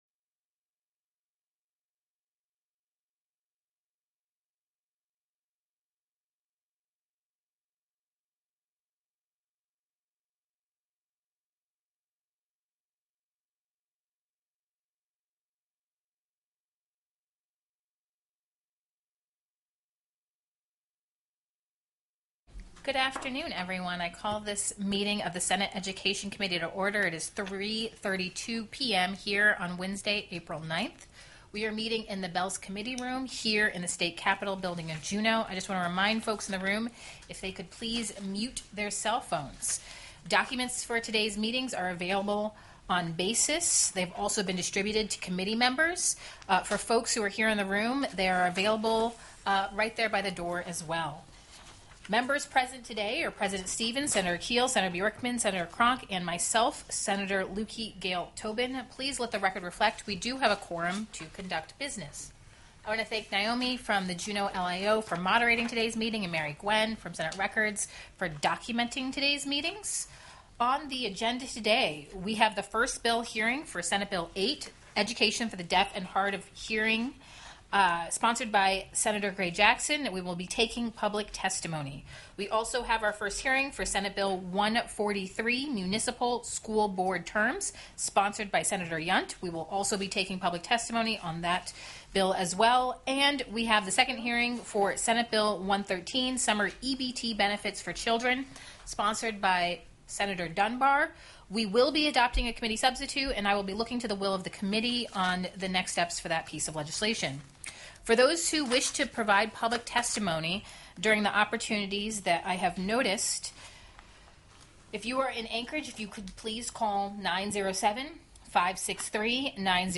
The audio recordings are captured by our records offices as the official record of the meeting and will have more accurate timestamps.
Heard & Held -- Invited & Public Testimony --